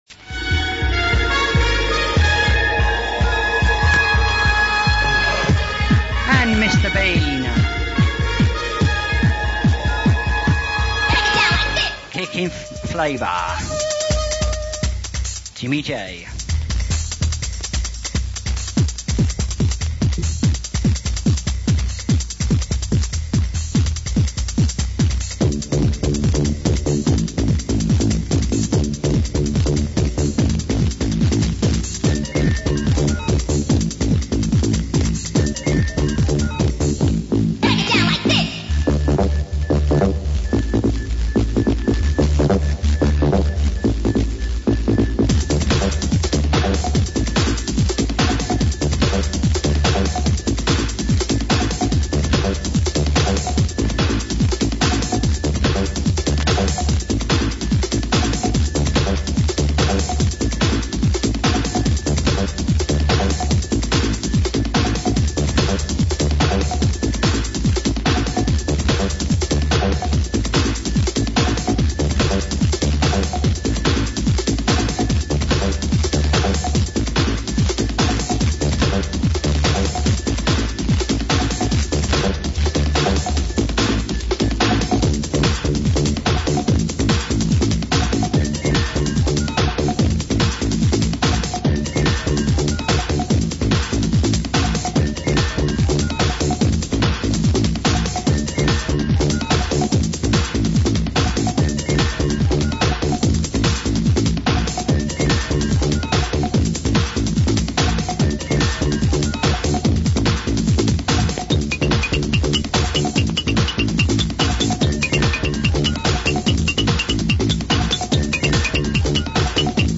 (quality a bit sus' on some tapes)
These tapes were recorded in Hammersmith, London and were some of the very first pirate radio stations I heard and recorded.
As with the Chillin' tapes, they had loads of adverts inbetween the music.